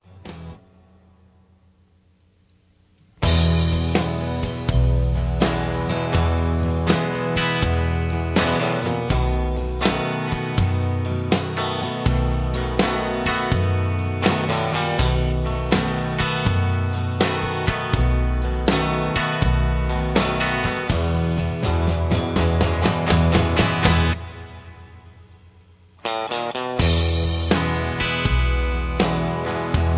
karaoke, mp3 backing tracks
backing tracks, r and b, rock, rock and roll